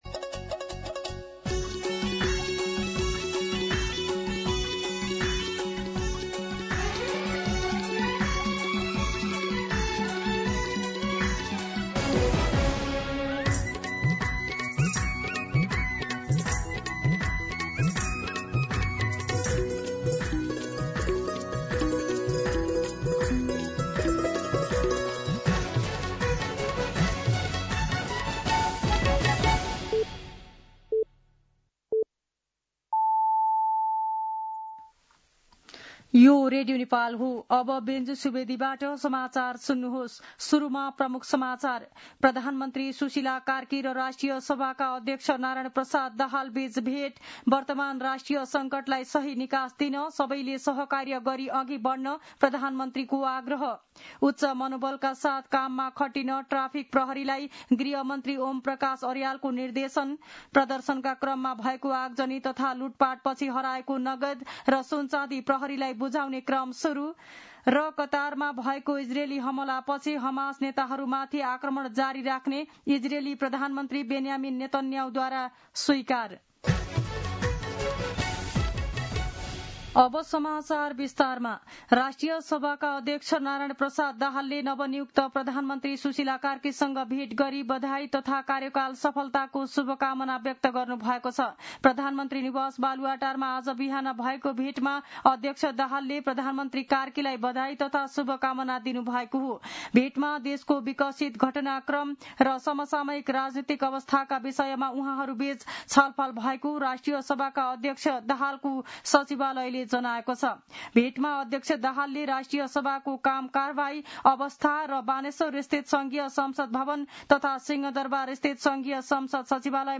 दिउँसो ३ बजेको नेपाली समाचार : ३१ भदौ , २०८२